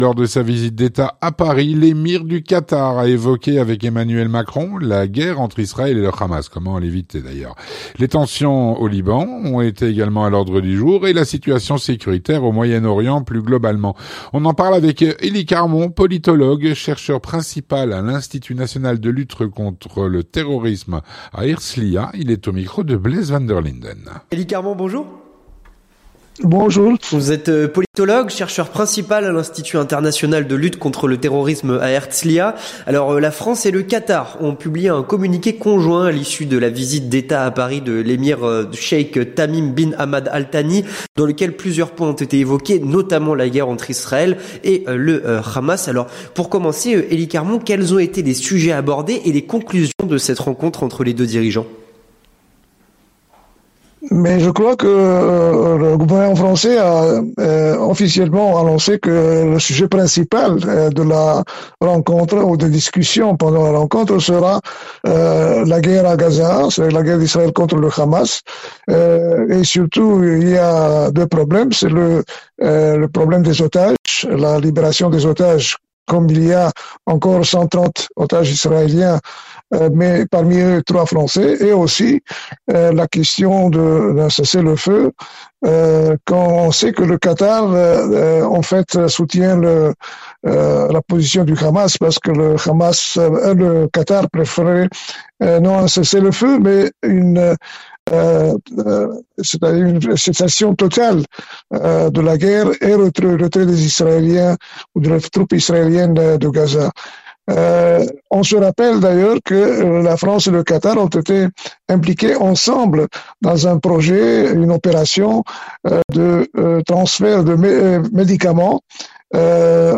L'entretien du 18H - La visite d’Etat à Paris de l’Emir du Qatar.